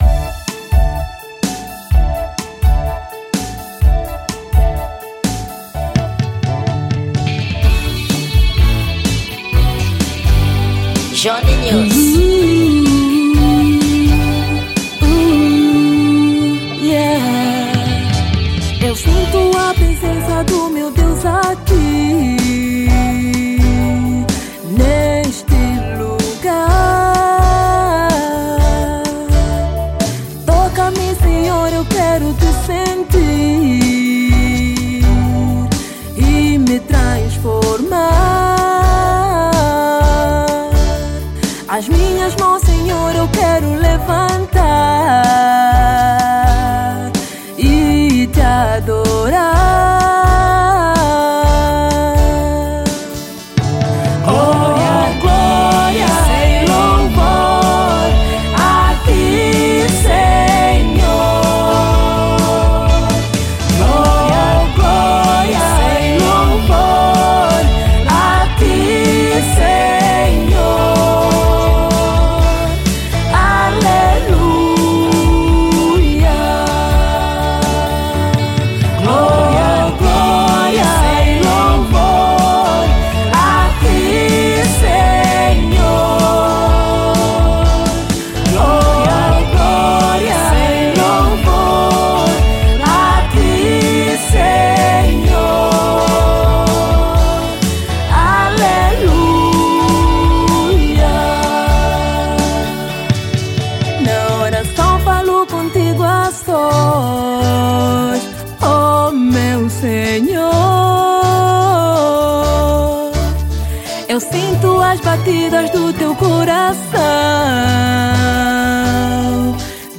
Gênero: Gospel